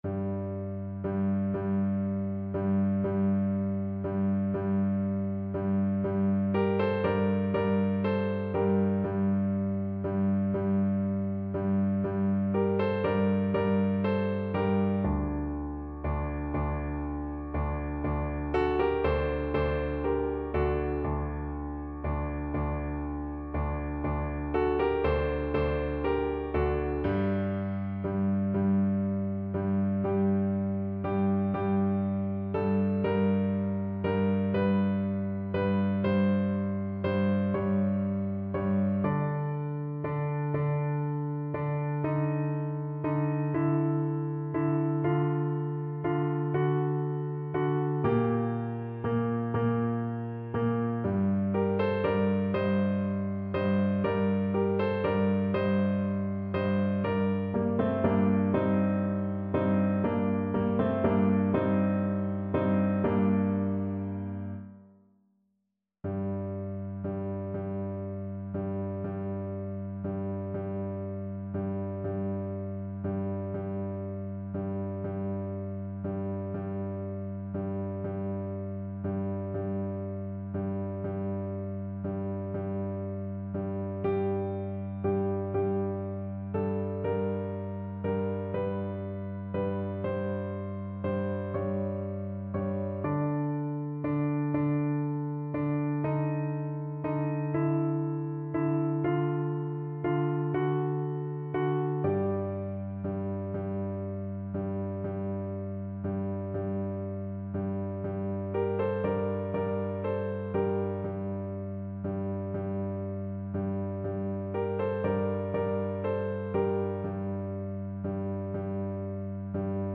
Alto Voice
= 60 Andante, con piombi
12/8 (View more 12/8 Music)
Classical (View more Classical Voice Music)